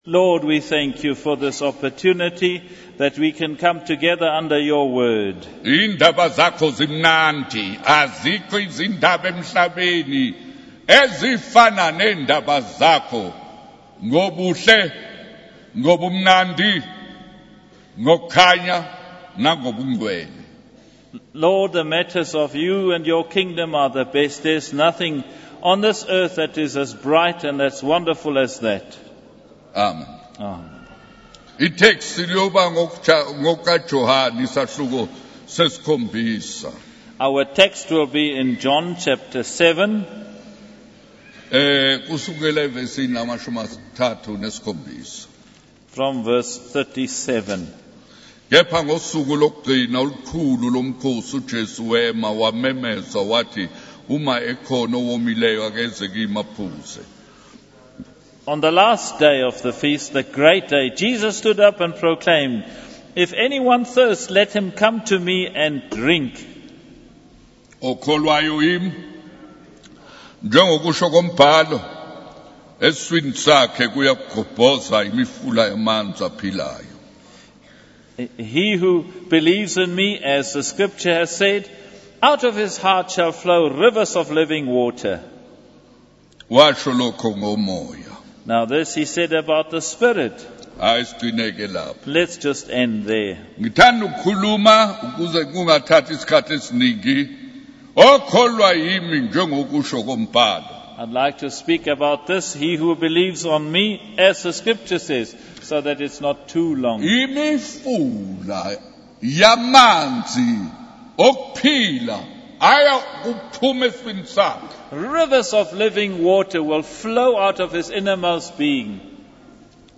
The preacher encourages the congregation to prioritize seeking God's approval rather than pleasing people.